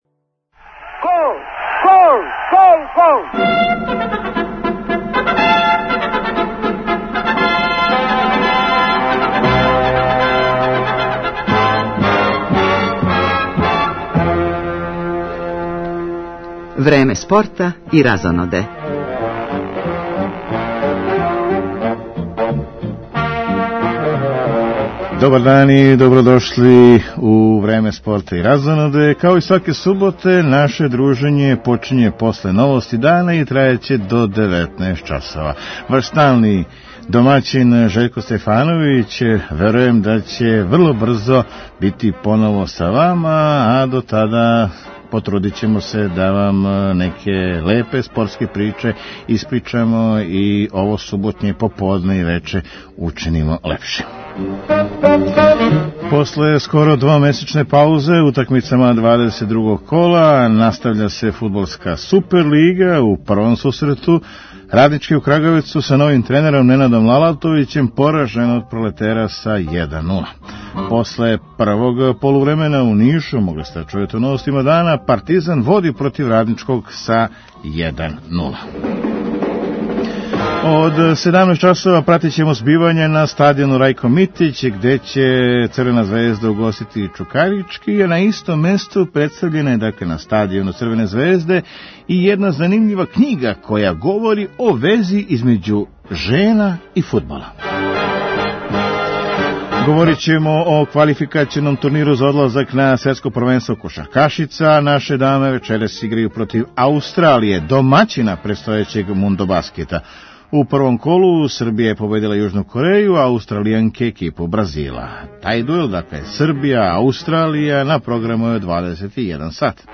Од 14 и 30 Партизан гостује Радничком у Нишу, док од 17 сати Црвена звезда, у још једном дербију, дочекује Чукарички. Са оба сусрета јавиће се наше колеге репортери и пратићемо збивања у Нишу, друго полувреме меча Раднички-Партизан и Београду са стадиона Рајко Митић.